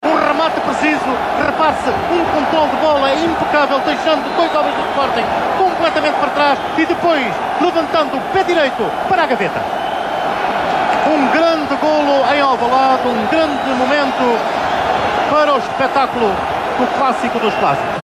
Que jogador assina um hat-trick na vitória do SL Benfica frente ao Sporting CP por 6 a 3, relatada por Gabriel Alves? Explicação Na época de 1993/1994 o SL Benfica vai a Alvalade vencer o Sporting CP por 6 a 3.